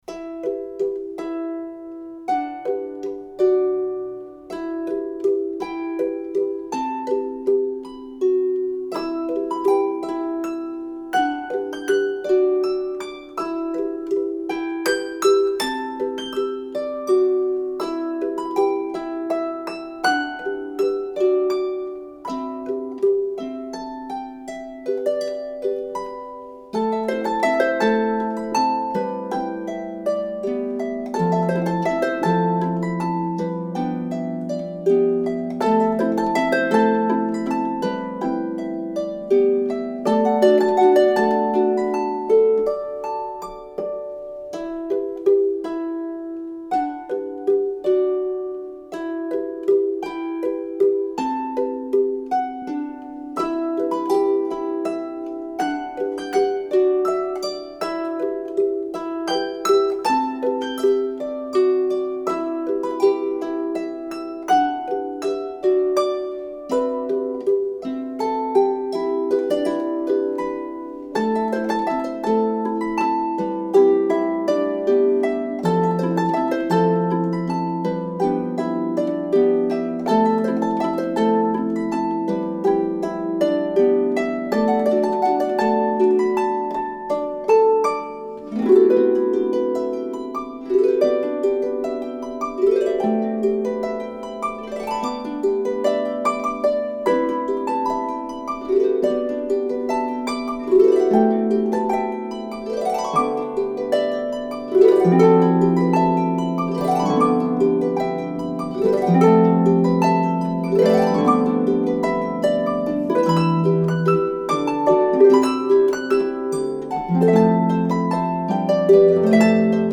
three lever or pedal harps
dreamy and fantasy-like piece
multi-level ensemble work